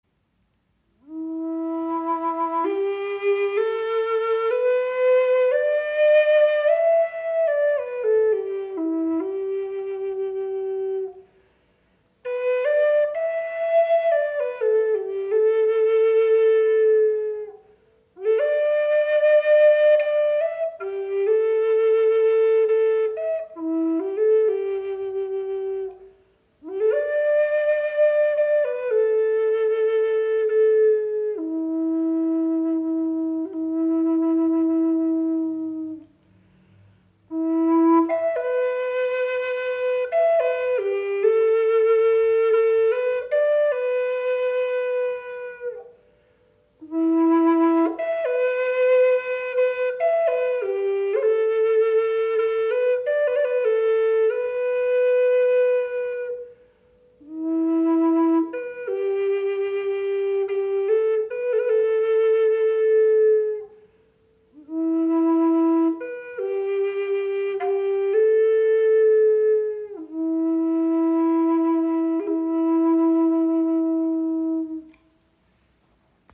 • Icon Handgefertigt aus Padouk mit Ahorn Schildkröten-Windblock
• Icon Gesamtlänge 62  cm, 25,4 mm Innenbohrung – warmer, klarer Klang
Präzise gestimmt entfaltet sie klare, harmonische Töne, die Herz und Seele berühren.
Erlebe den klaren Klang dieser handgefertigten Padouk-Flöte in E-Moll.
Die präzise Intonation, das weiche Ansprechverhalten und der volle Klang machen sie zu einem treuen Begleiter auf jeder musikalischen und spirituellen Reise.